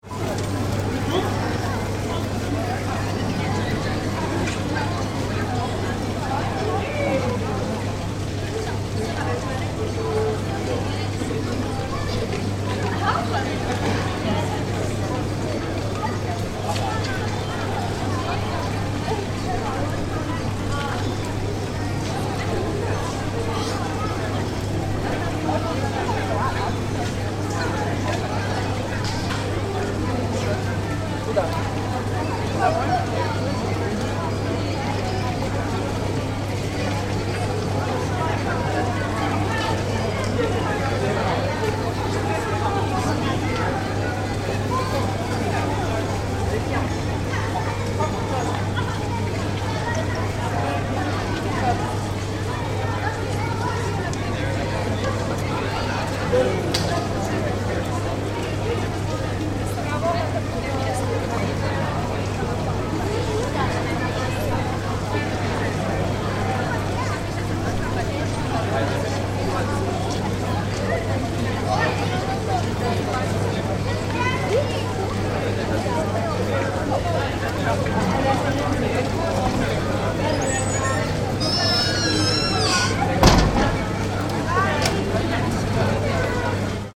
Street Ambiance, Dubrovnik Old Town (sound FX)
Dubrovnik Old Town Street Ambiance. Lots of pedestrian activity. Voices and hubbub. Sound of nearby restaurants. Groups of children laugh and chatter. No cars or lorries. Doors swing open and close. 16 bit 48 kHz Stereo WAV
DubrovnikOldTownStreetAtmos_plip.mp3